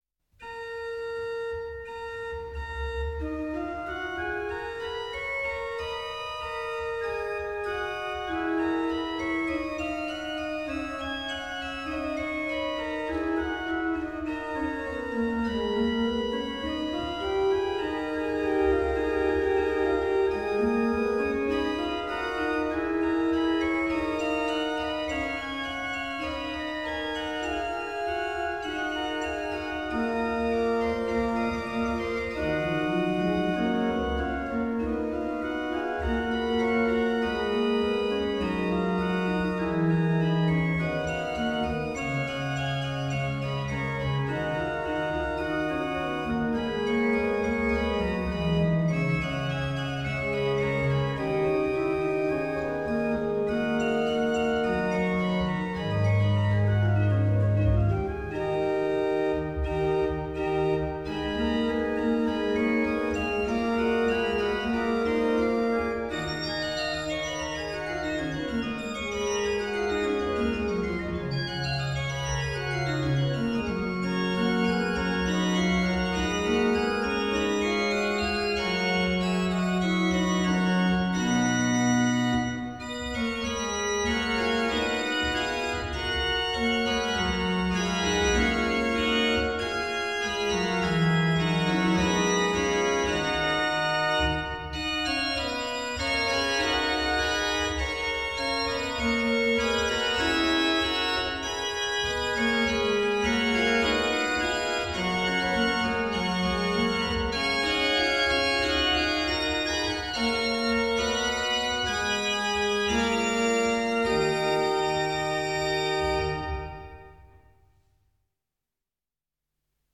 Kostol sv. Jána Krstiteľa
Organový pozitív I / 6